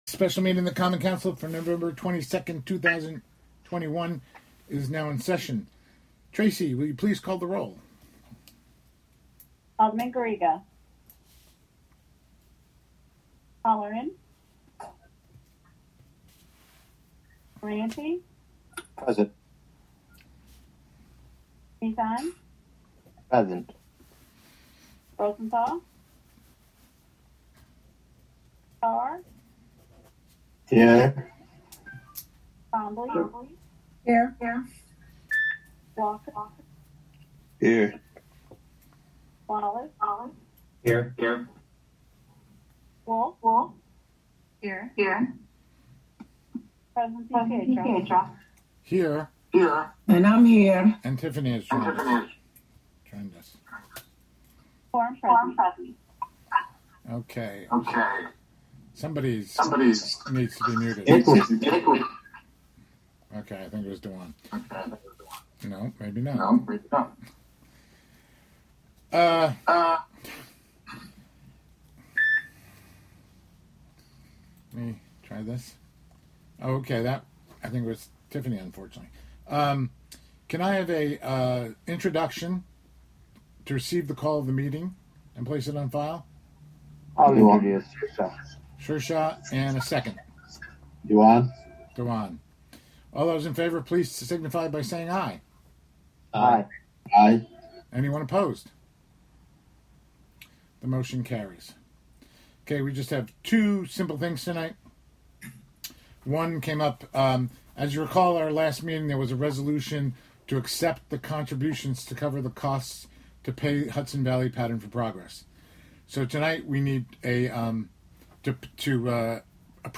Live from the City of Hudson: Hudson Common Council Special Meeting (Audio)